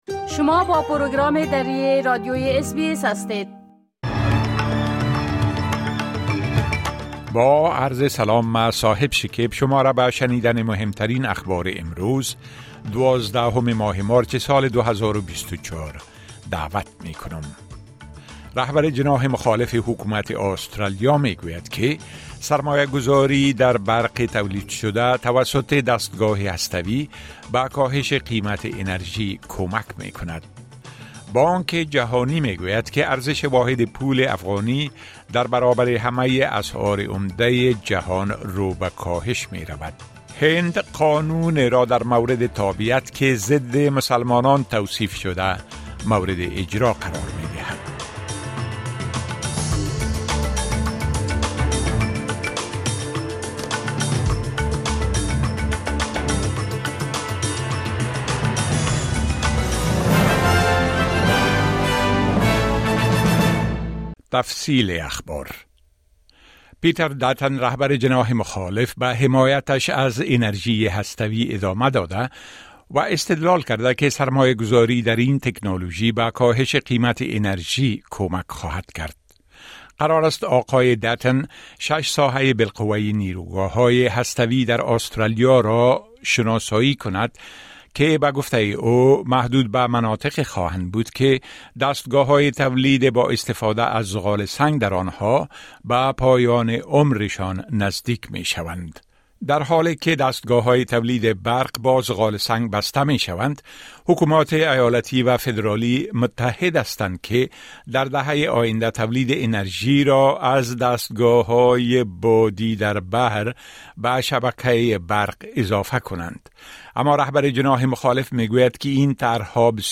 اخبار مهم امروز ۱۲ مارچ ۲۰۲۴ به زبان درى از اس بى اس را در اينجا شنيده مى توانيد.